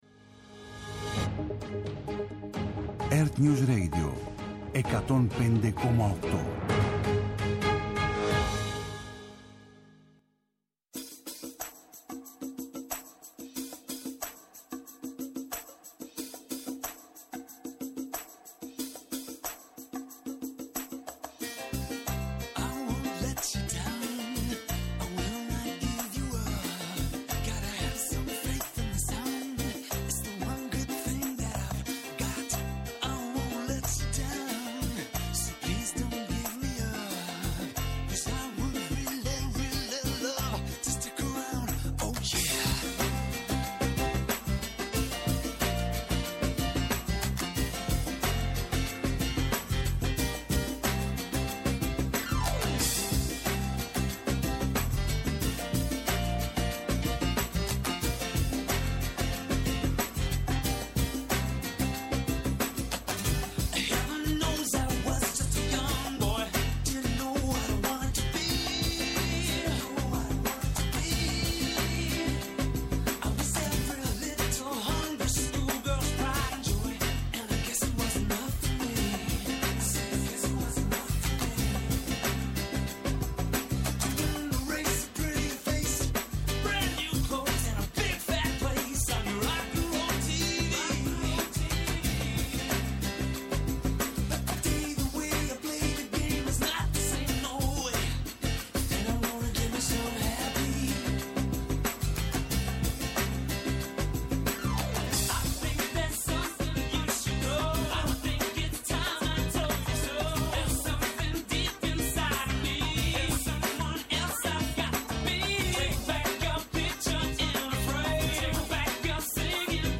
Eκτακτη ενημερωτική εκπομπή απόψε 20:00-21:00